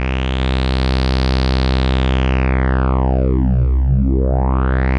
Filter Sweep Demo
filter_sweep.wav